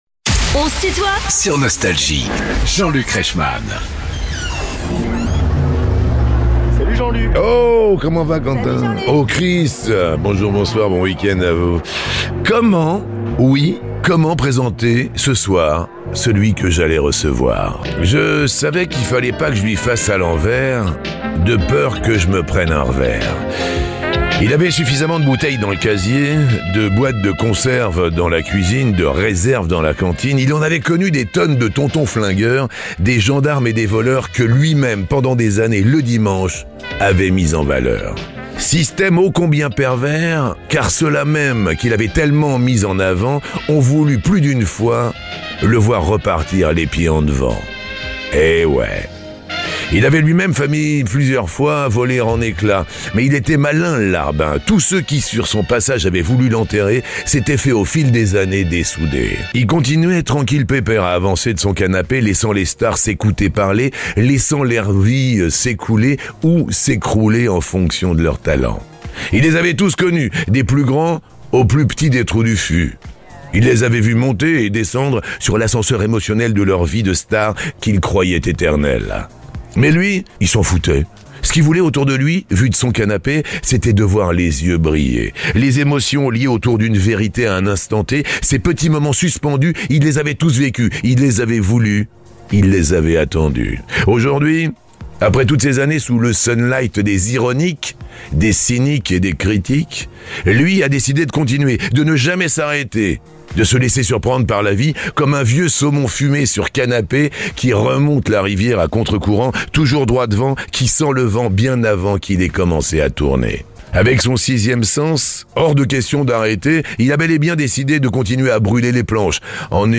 Beschreibung vor 7 Monaten A l'occasion de son grand retour en prime time en juin dans "Familles, je vous aime", une émission qui réunit les artistes et leurs parents, Michel Drucker est l'invité de "On se tutoie ?..." avec Jean-Luc Reichmann, vendredi 9 mai, de 19h à 20h. L'animateur emblématique nous racontera les moments forts de son incroyable carrière !